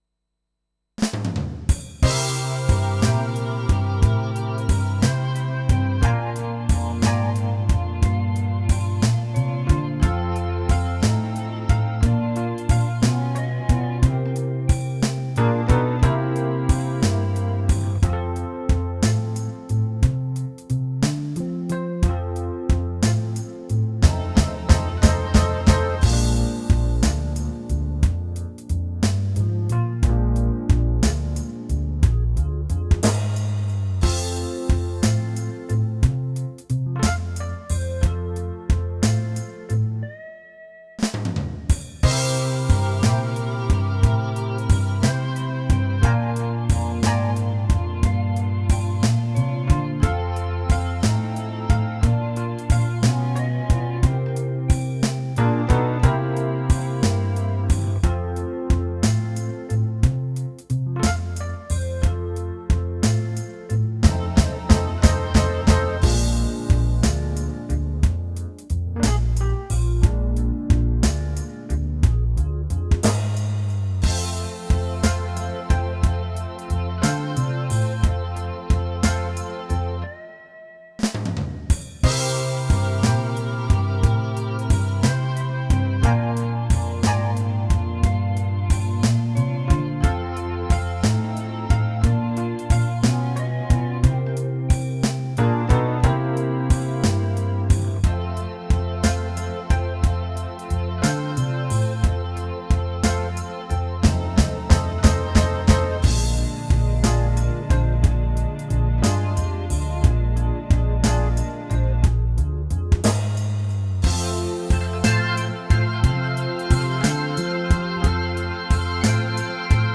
BLUESF.wav